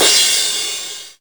CRASH07   -R.wav